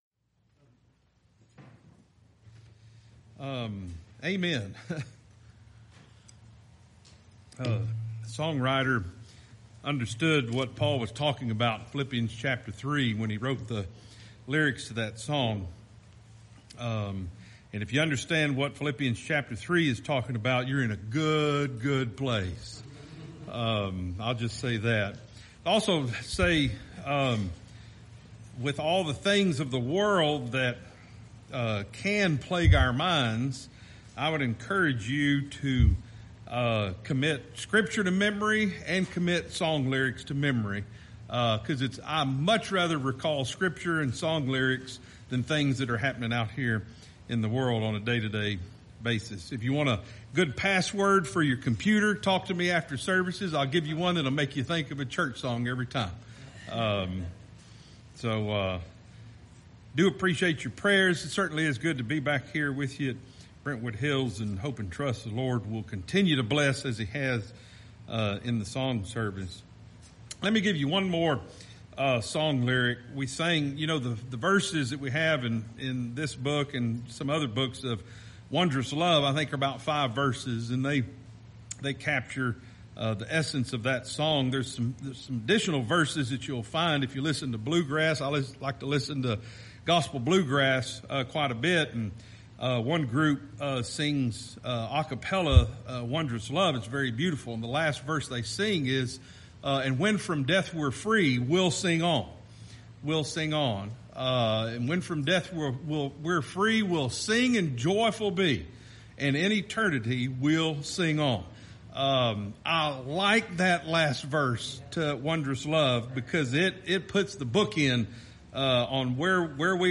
BHPBC Sermon podcast